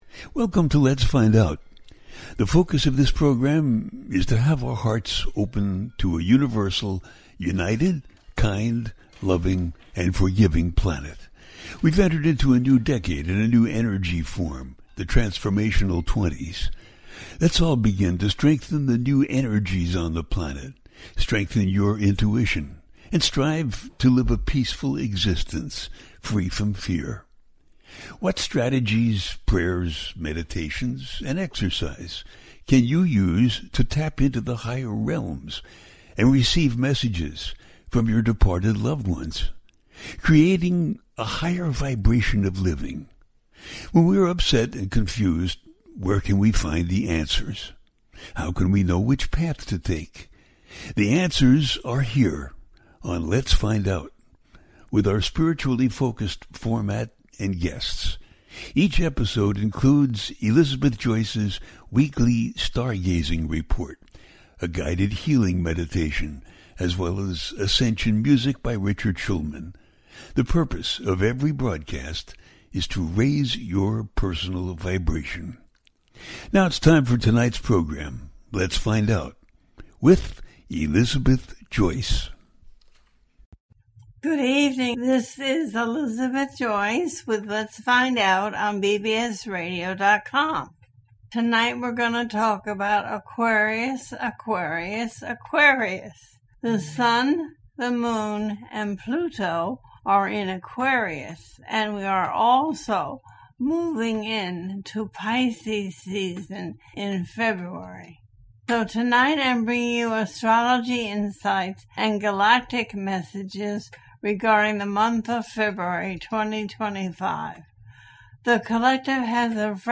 Talk Show Episode
Aquarius, Aquarius, Aquarius and The Harmonizing of DNA - A teaching show
The listener can call in to ask a question on the air.
Each show ends with a guided meditation.